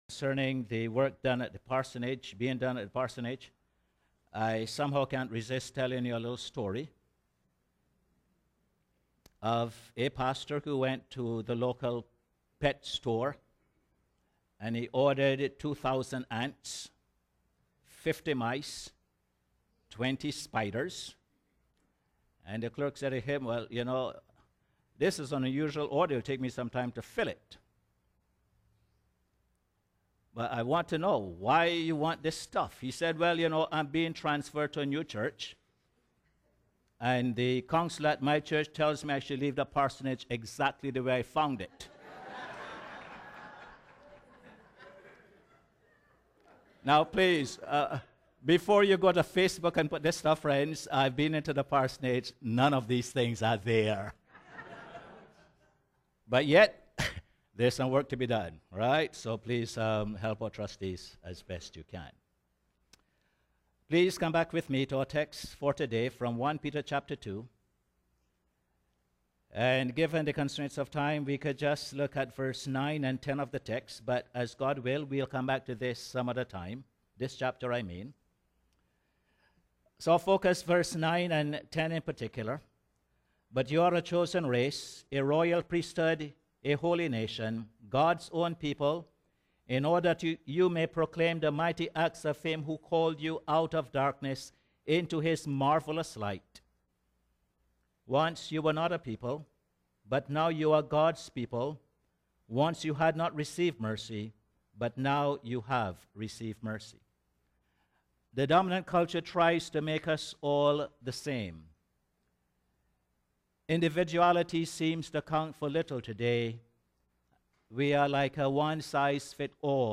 Posted in Sermons on 20.